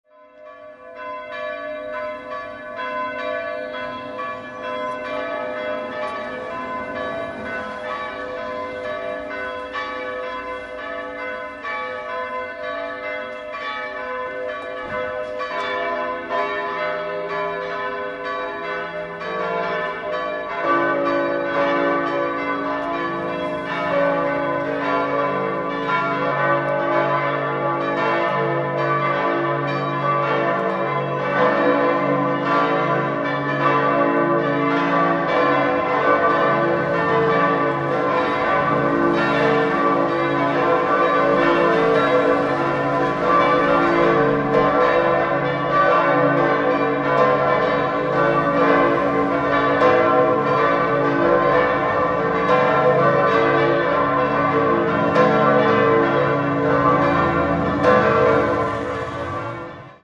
7-stimmiges Geläute: c'-d'-f'-g'-a'-c''-d''
bell
Die sieben Glocken im Südturm des Ingolstädter Münsters bilden das glockenreichste Gesamtgeläute der Stadt. Aufgrund seiner verhältnismäßig dezenten Lautstärke wird dieses Klangerlebnis aber durch die meist sehr laute Umgebung gestört.